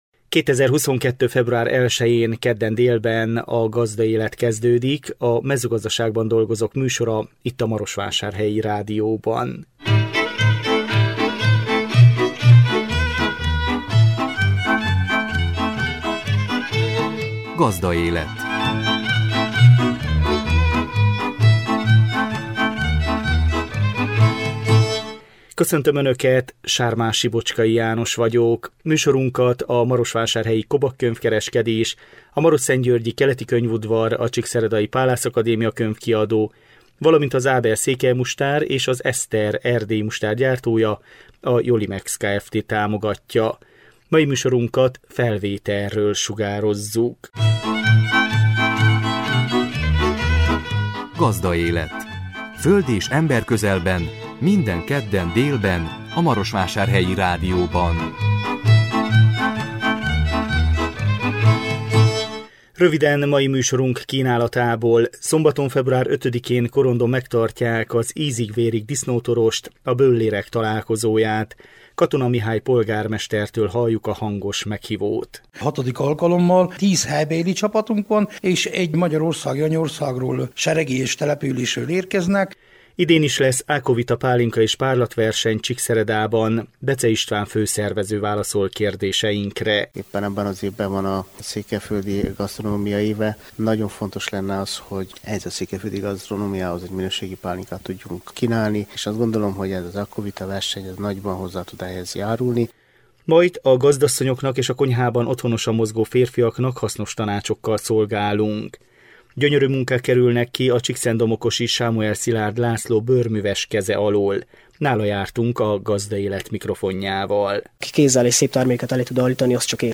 A 2022 február 1-én jelentkező műsor tartalma: Szombaton, február 5-én Korondon megtartják az Ízig-vérig disznótorost, a böllérek találkozóját. Katona Mihály polgármestertől halljuk a hangos meghívót.